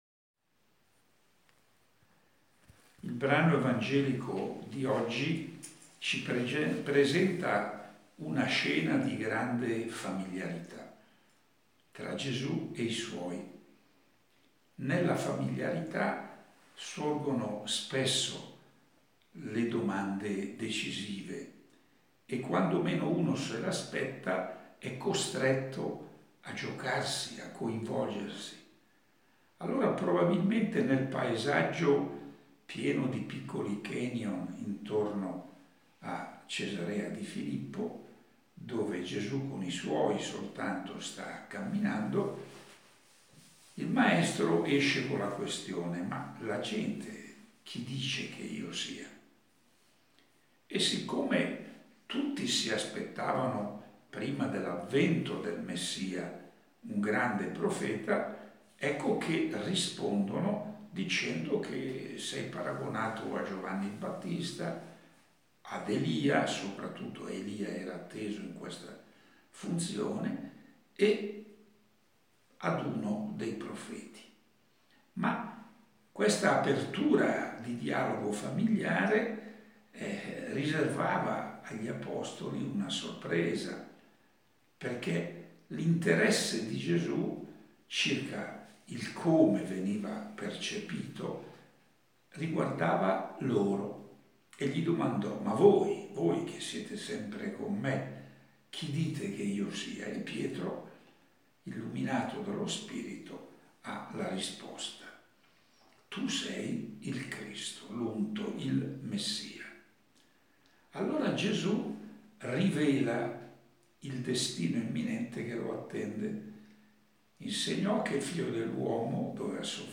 Proponiamo la registrazione audio dell’omelia pronunciata dal cardinale Scola il 30 marzo 2020, lunedì della quinta settimana della Quaresima ambrosiana, presso la cappellina della sua abitazione di Imberido (LC).